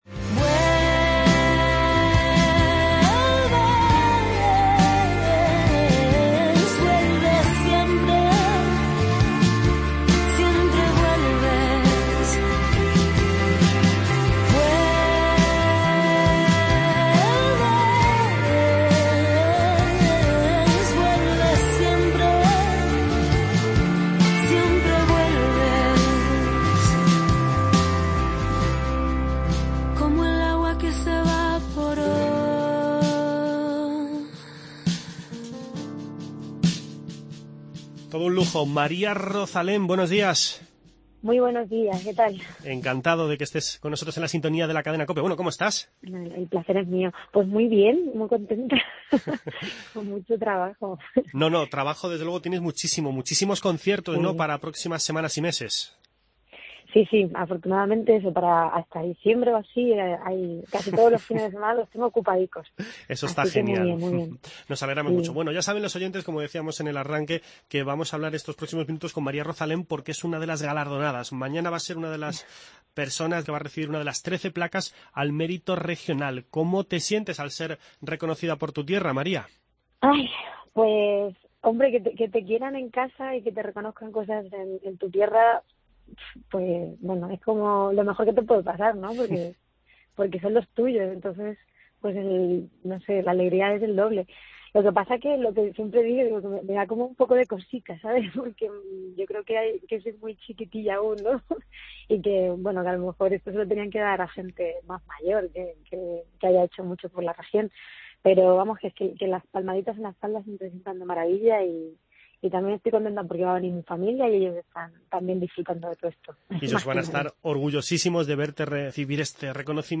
Castilla-La Mancha celebrará mañana el Día de la Región. Hoy en nuestro magacine charlamos con la albaceteña María Rozalén, que este martes recibirá la Placa al Mérito Regional.